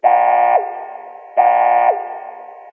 foKlaxonA.ogg